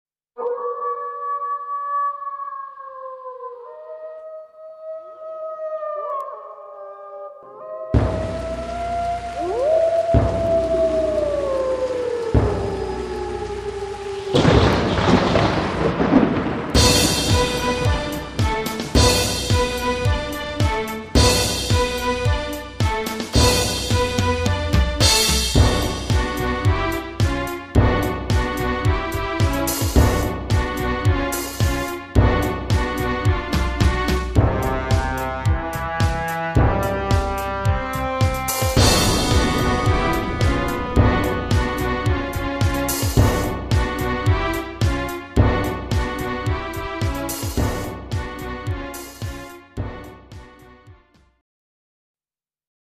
Home : Dwarsfluit :